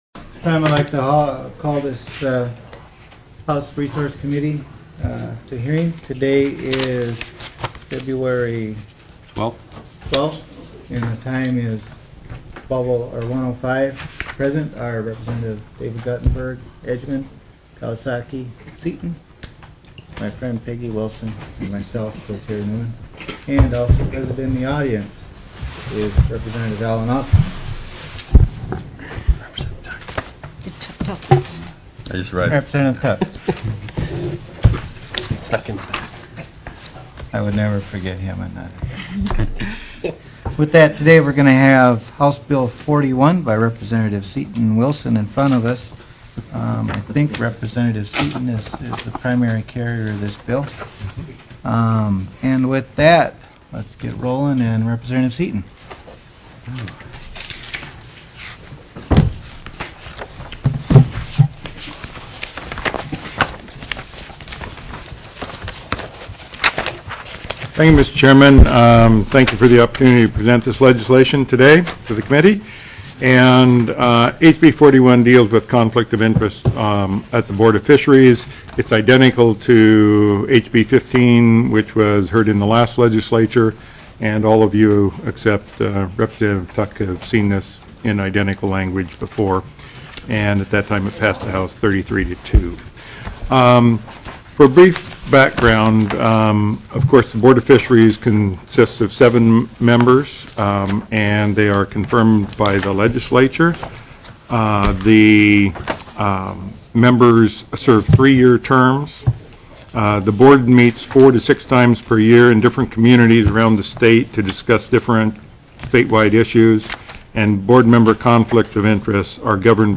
02/12/2010 01:00 PM House RESOURCES